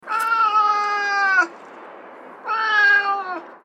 دانلود صدای گربه غمگین در خیابان از ساعد نیوز با لینک مستقیم و کیفیت بالا
جلوه های صوتی